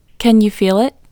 LOCATE IN English Female 37